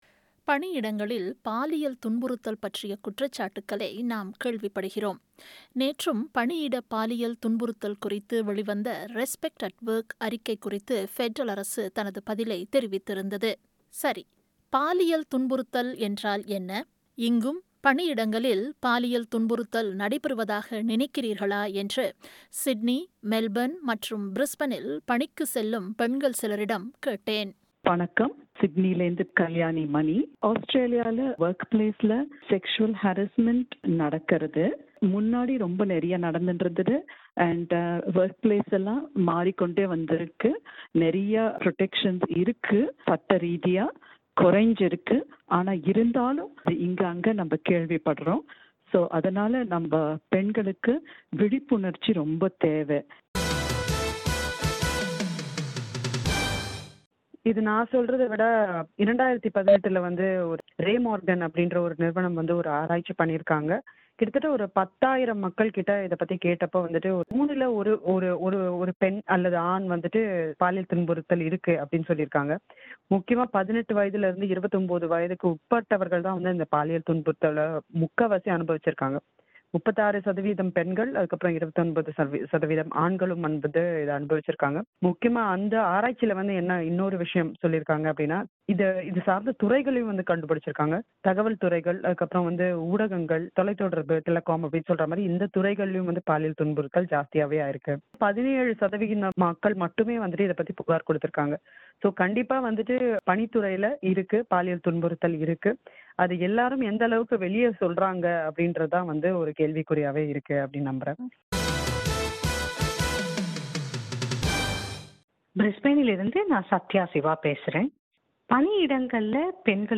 This feature brings the views of three working women about workplace sexual harassment